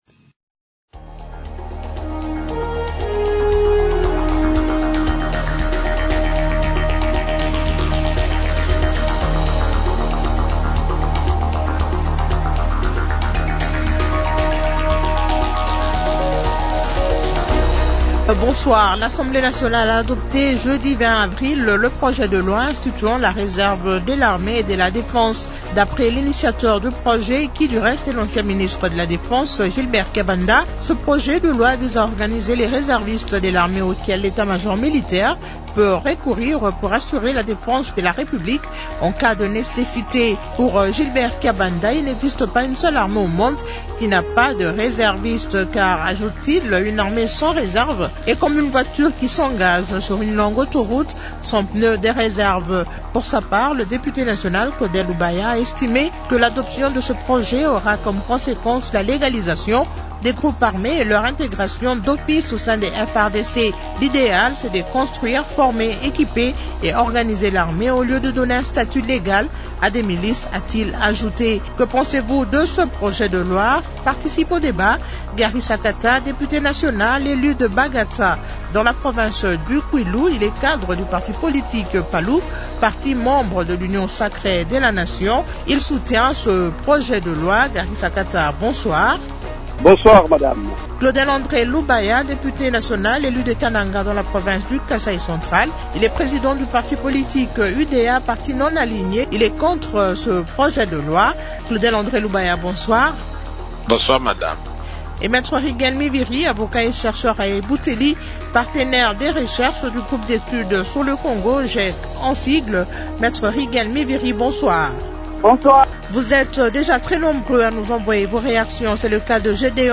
Débat sur le projet de loi portant réserve de l’armée et de la défense